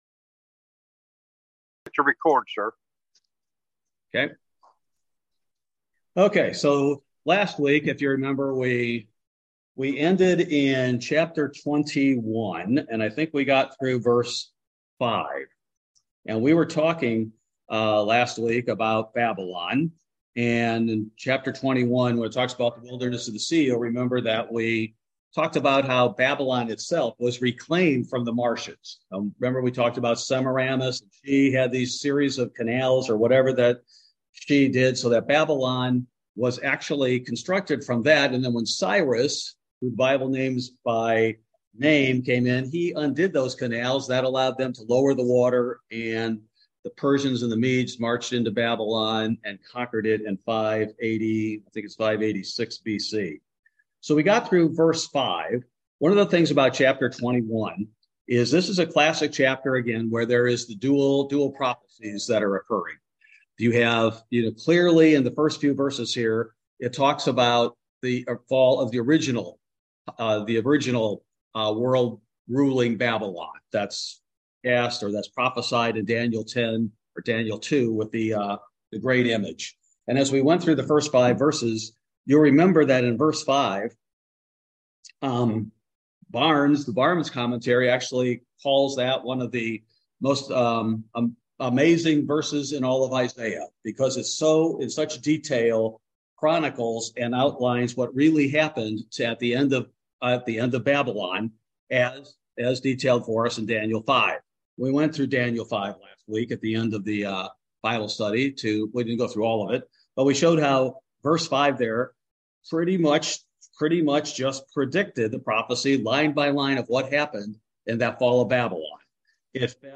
Bible Study: December 28, 2022